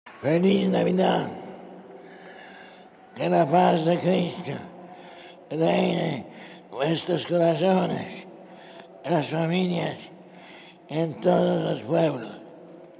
En una lluviosa jornada en la Plaza de San Pedro, Juan Pablo II ha pedido “ante el pesebre donde yace indefenso” el profeta de la Paz, “que se apaguen tantos focos de tensión , que corren el riesgo de degenerar en conflictos abiertos; que se consolide la voluntad de buscar soluciones pacíficas , respetuosas de las legítimas aspiraciones de los hombres y de los pueblos”.
Después tras el Mensaje a todos los pueblos de la tierra, el Papa ha felicitado las fiestas navideñas en 62 lenguas, entre ellas en español.